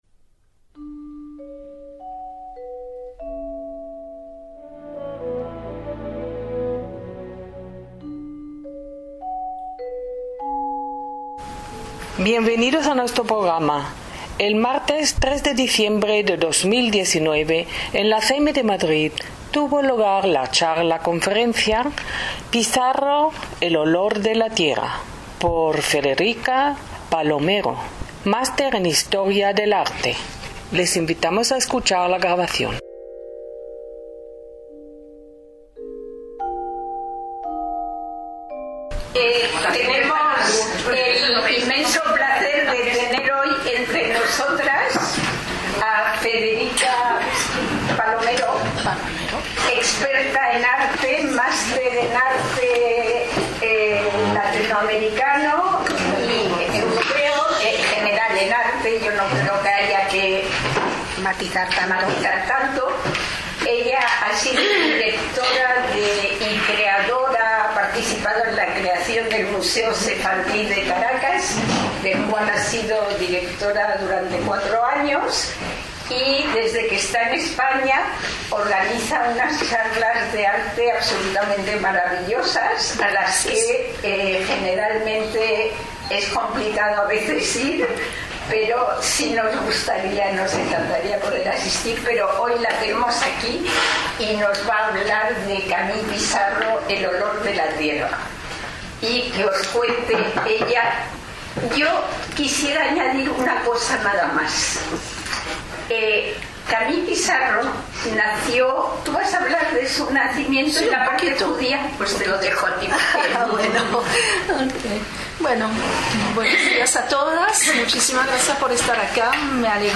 CEMI, Madrid
ACTOS EN DIRECTO